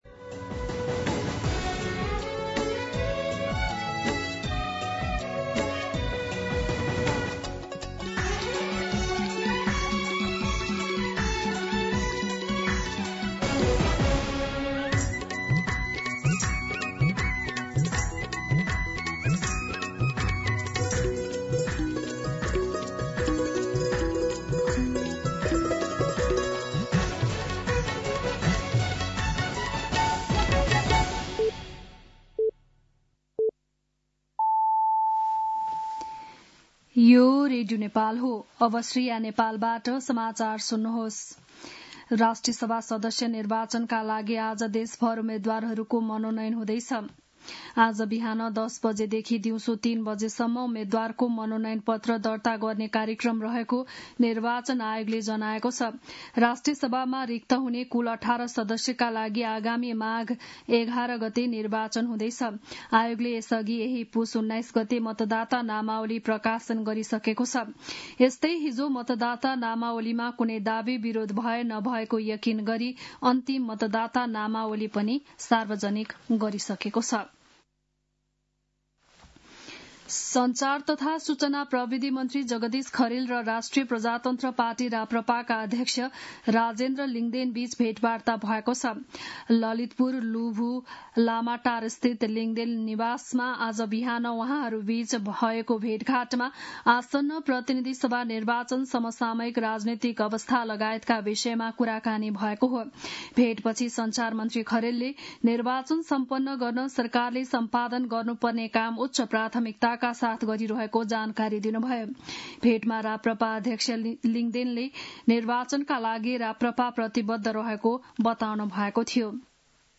बिहान ११ बजेको नेपाली समाचार : २३ पुष , २०८२
11-am-Nepali-News-2.mp3